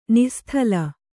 ♪ nih sthala